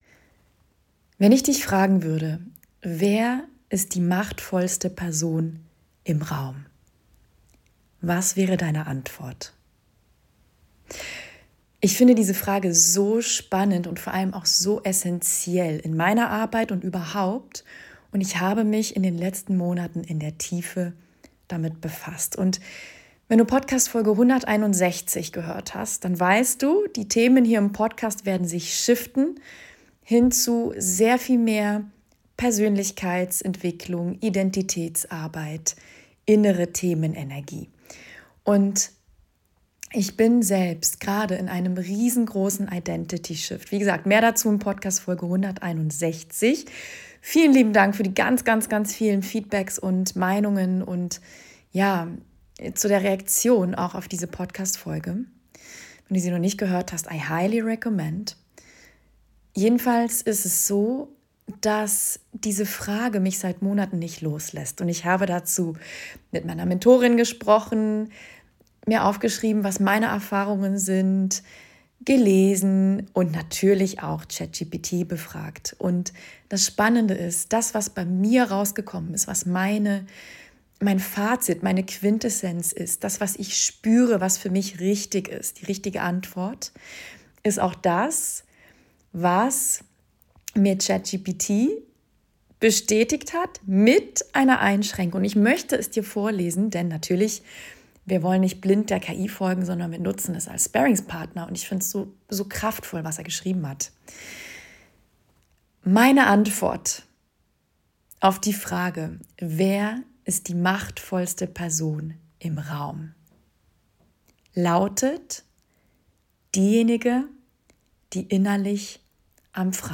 Real, raw und ungeschnitten.